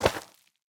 Minecraft Version Minecraft Version latest Latest Release | Latest Snapshot latest / assets / minecraft / sounds / block / netherwart / step2.ogg Compare With Compare With Latest Release | Latest Snapshot